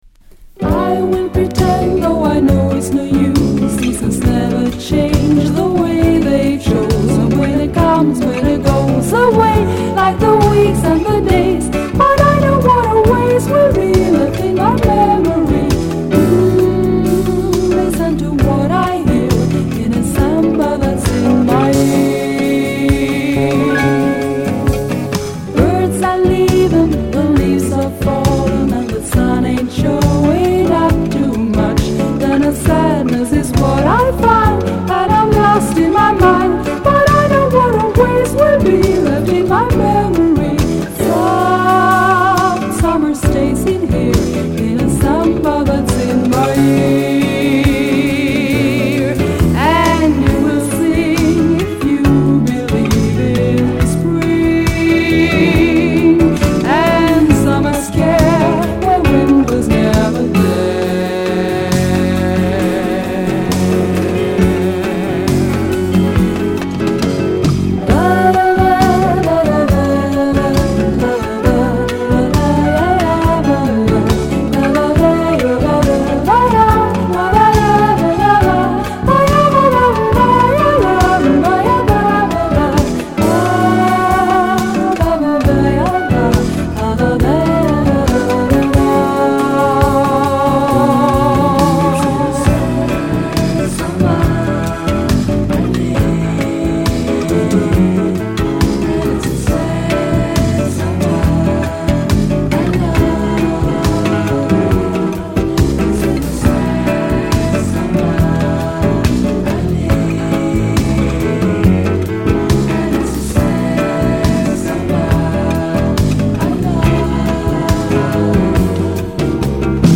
スウェーデンのピアノ奏者